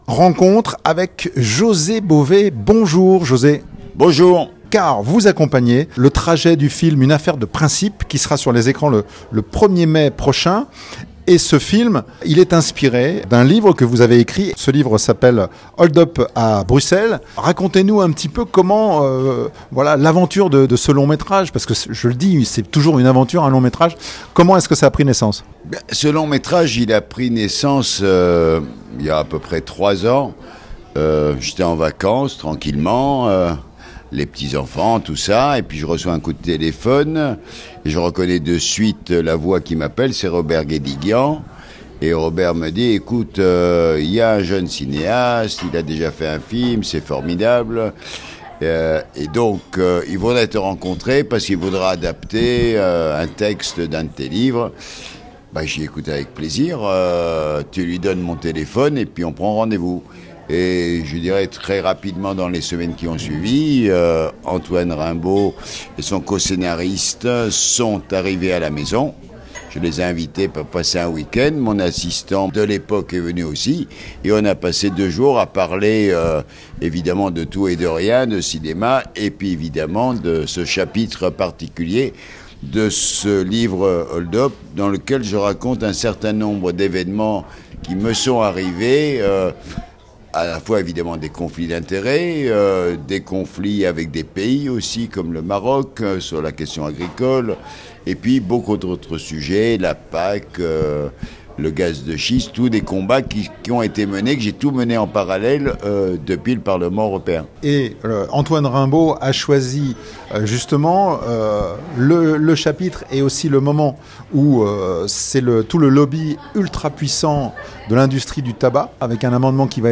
Inspiré du livre "Hold-up à Bruxelles" de José Bové Avec Bouli Lanners dans le rôle de José Bové Sortie le 1er mai 2024 Dans cette interview, son regard sur le mouvement agricole